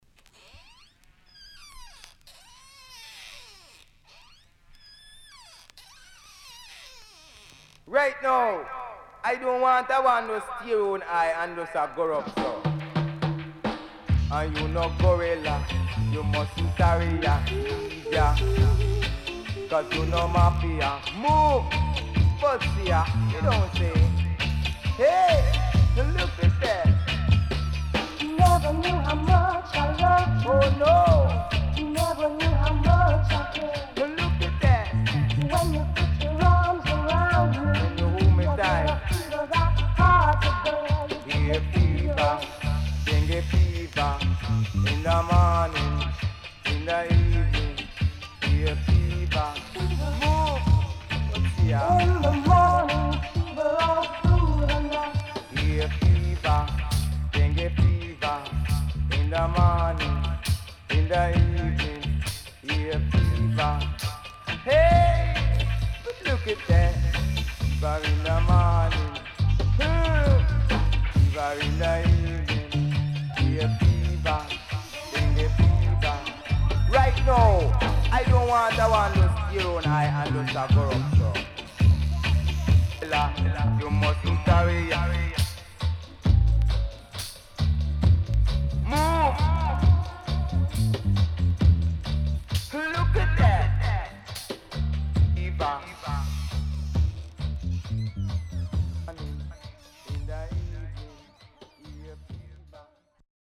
Very Cool & Deep Deejay Album
SIDE A-1出だしノイズあり。少しチリノイズ入ります。盤面は所々うすいこまかい傷ありますがきれいです。